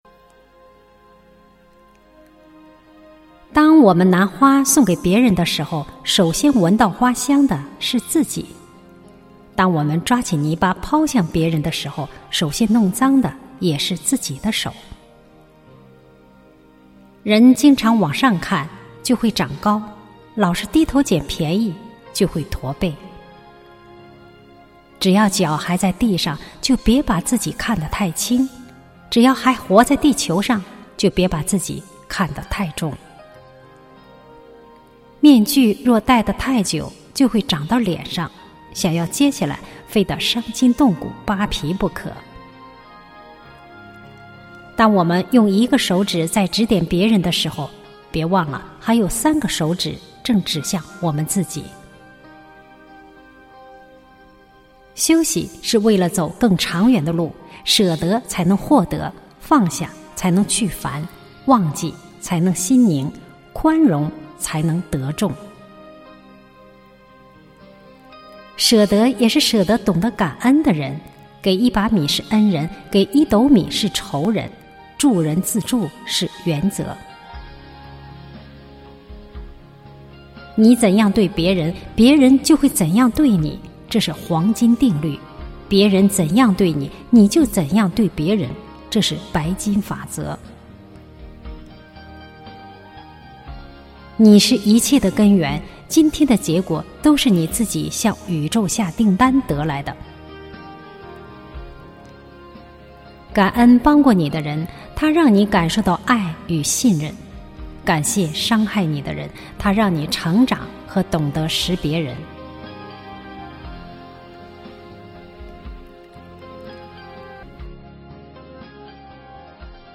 朗诵｜换位